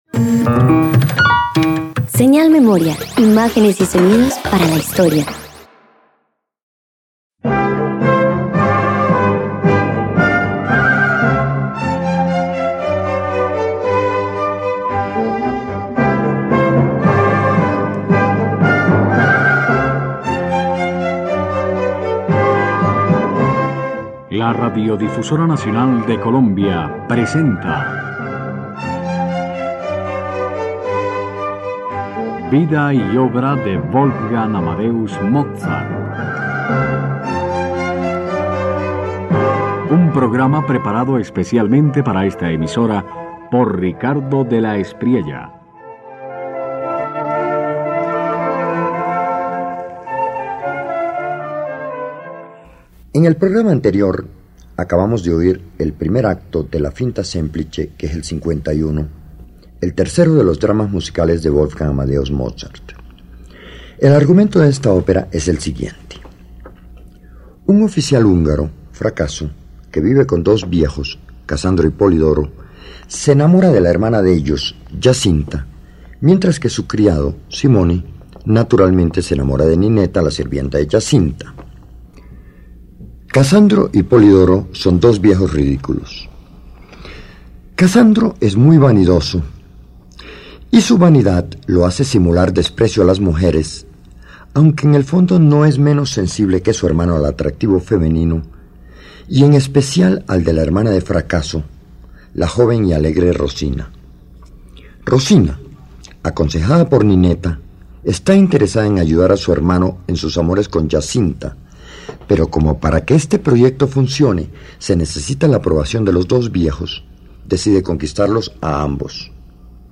A medida que la trama se complica, Mozart intensifica los contrastes: ritmos más vivos, giros inesperados y voces que se persiguen en enredos musicales ingeniosos. No convierte la confusión en ruido, sino en claridad expresiva y fuerza dramática.